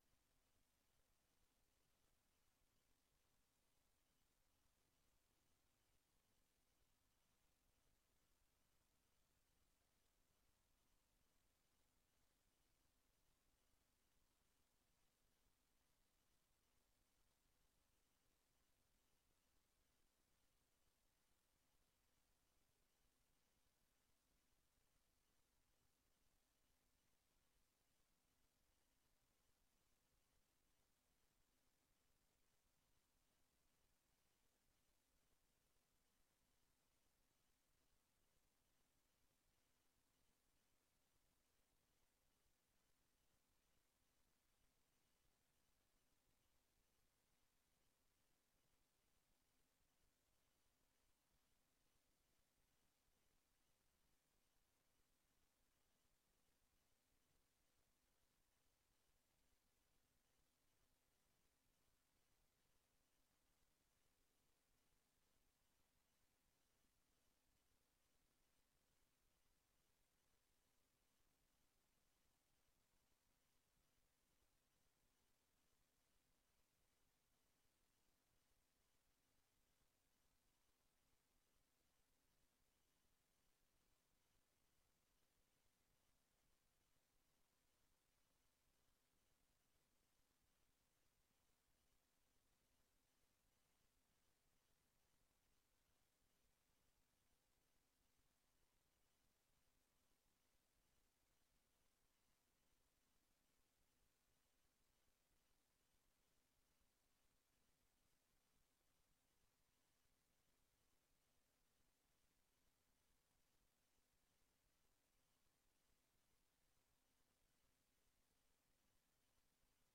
Raadsinformatieavond (SESSIE B)